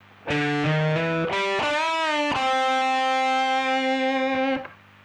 Coole Blues Riffs
Riff-Variationen, Clicheés mit der A-Moll Pentatonik:
Beim Saitenziehen (Bending) darauf achten, daß man mindestens zwei Finger auf die Saite stellt, die gezogen wird (Stützfinger in Klammern).